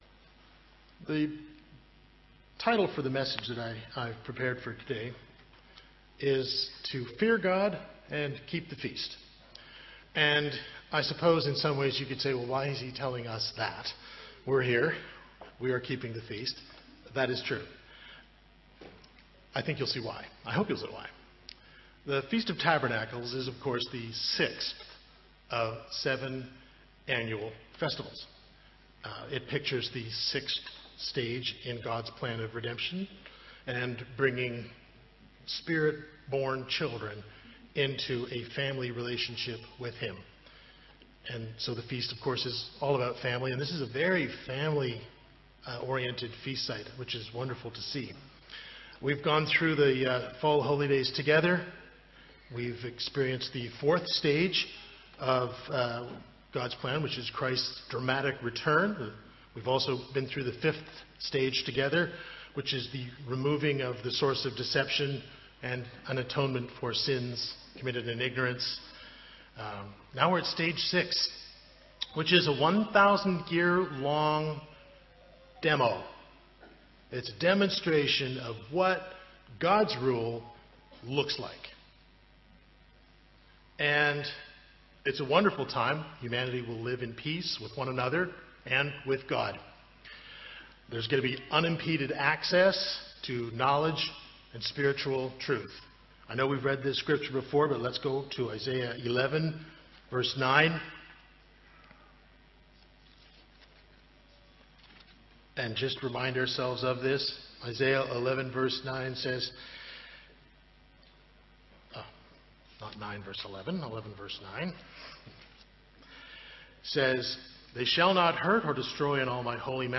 This sermon was given at the Montego Bay, Jamaica 2019 Feast site.